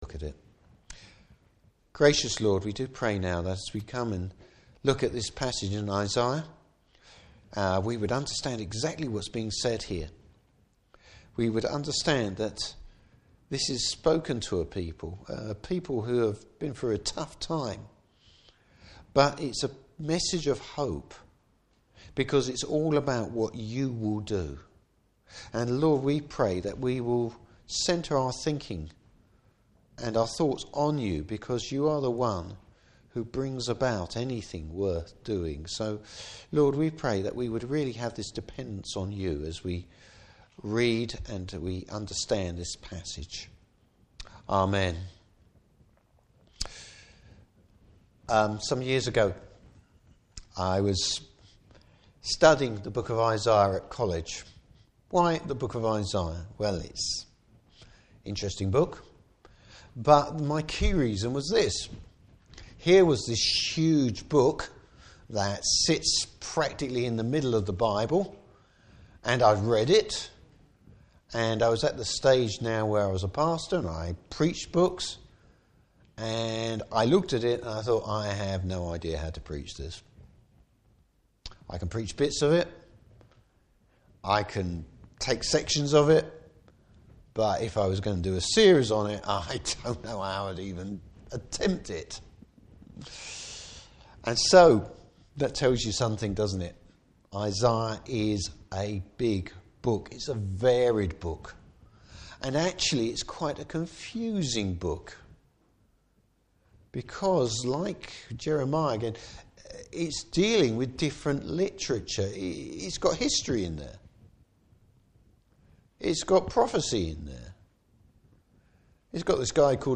Service Type: Morning Service Proclamation of the future restoration of God’s people.